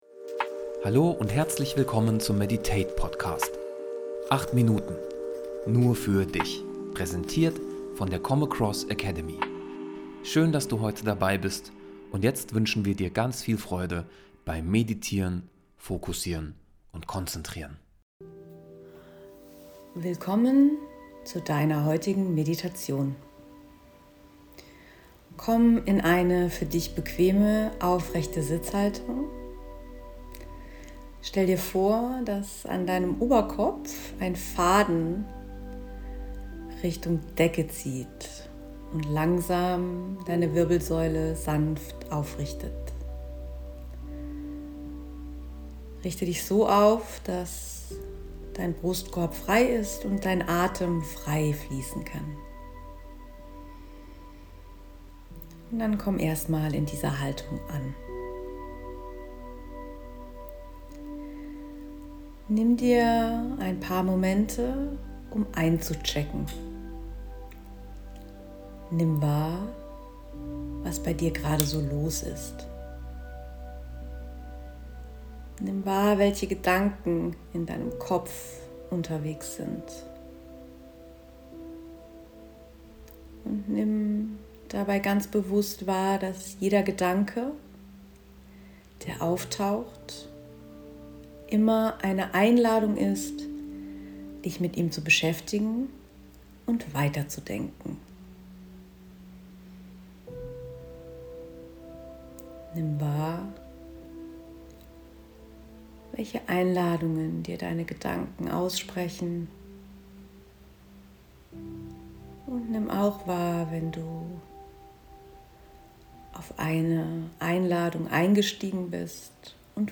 Check-In Meditation